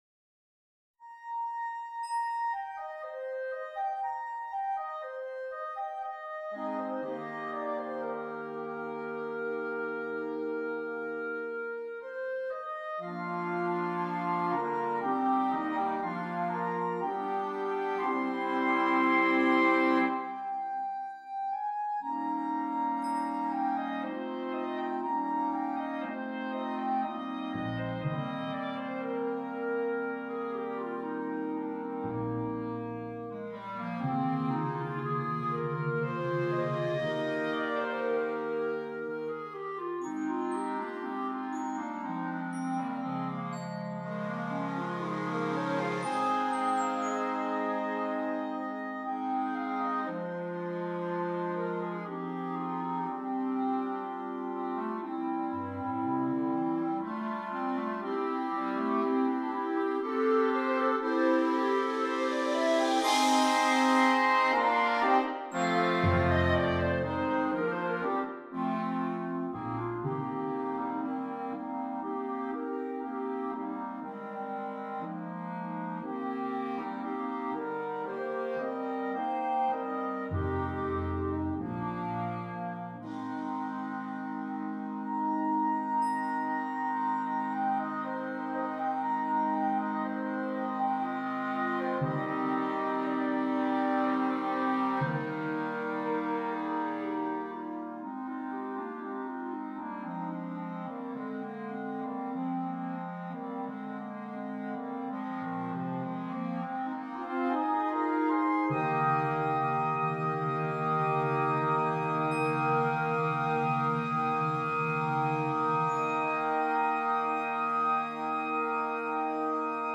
Voicing: Clarinet Octet